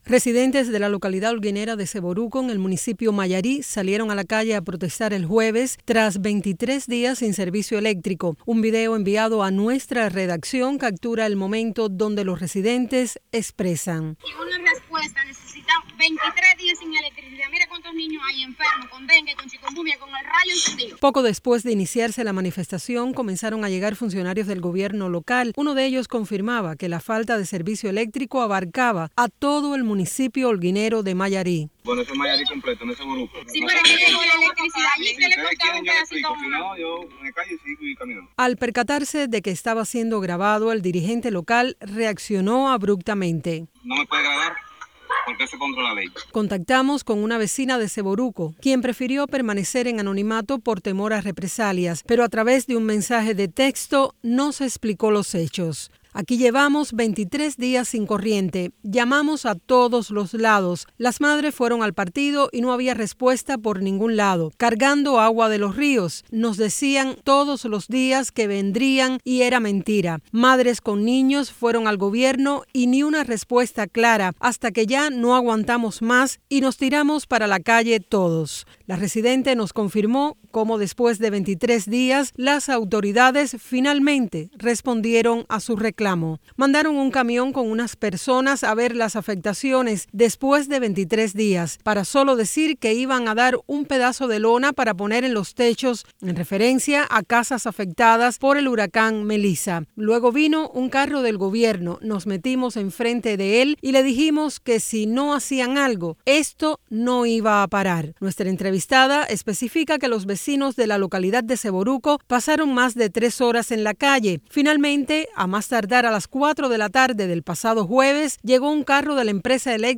Protesta en Seboruco
Un video enviado a nuestra redacción captura el momento donde de observa a los residentes reclamar: “Una respuesta necesitamos, 23 días sin electricidad. Mira cuántos niños hay enfermos, con dengue, con chikungunya, con el rayo encendido”.